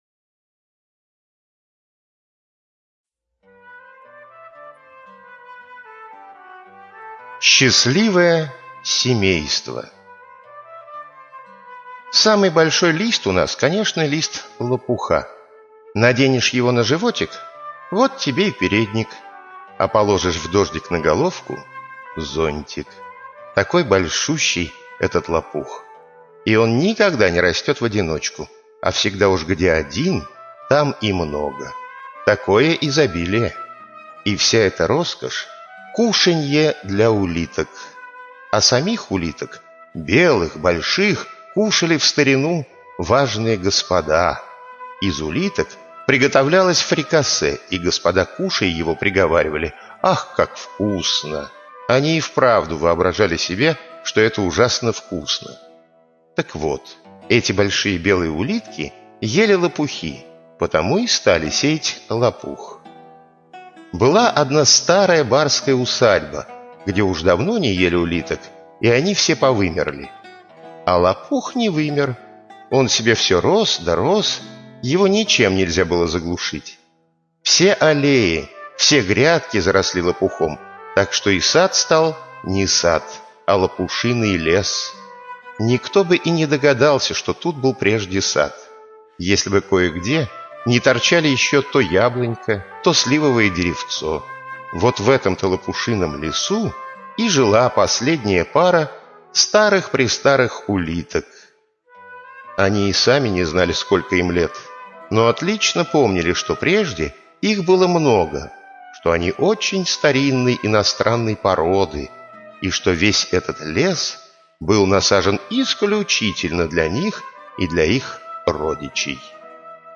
Счастливое семейство - аудиосказка Андерсена. Добрая история о счастливой жизни улиток в лопушином лесу.